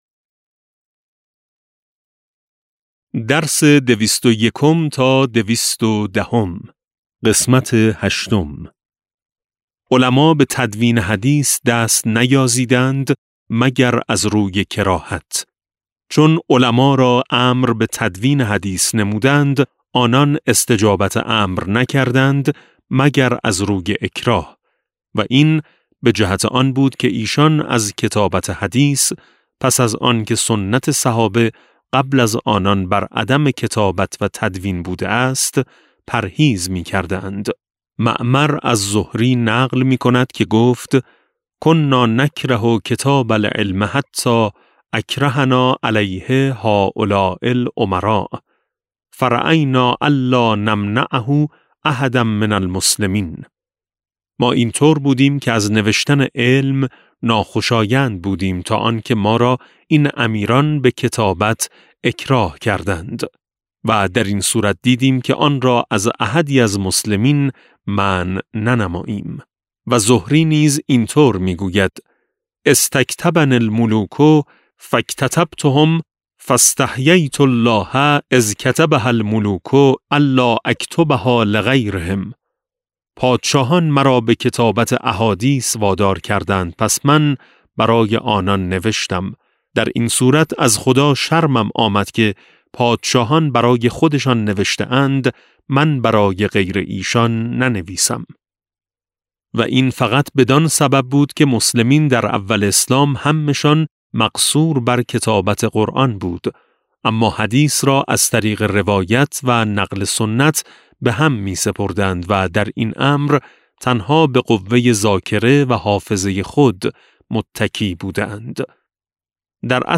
کتاب صوتی امام شناسی ج14 - جلسه16